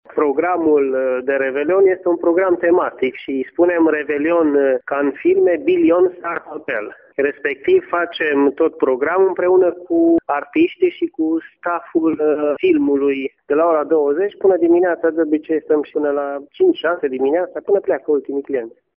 Directorul de vânzări